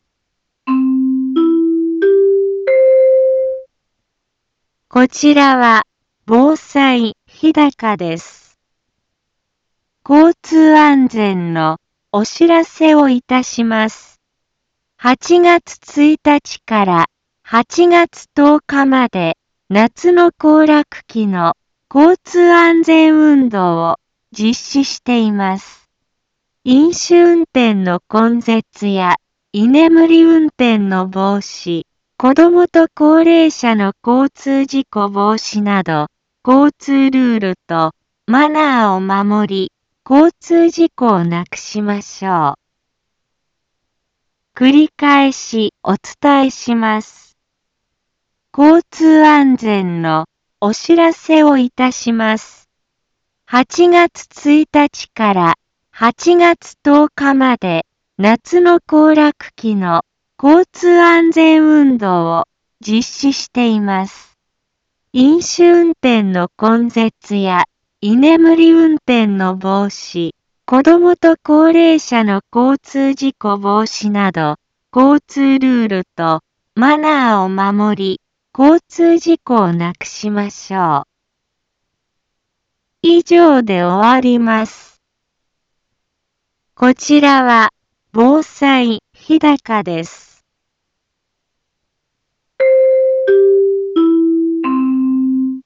Back Home 一般放送情報 音声放送 再生 一般放送情報 登録日時：2021-08-02 15:03:23 タイトル：夏の行楽期の交通安全運動について インフォメーション：こちらは防災日高です。